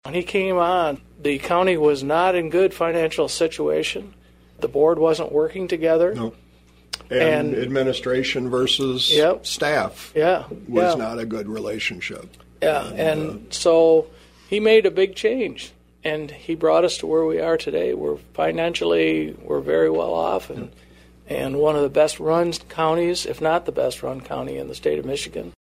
Ottawa County Board Chairman Roger Bergman of Grand Haven and fellow Commissioner Joe Baumann of Holland Township admitted that filling the vacancy won’t be easy.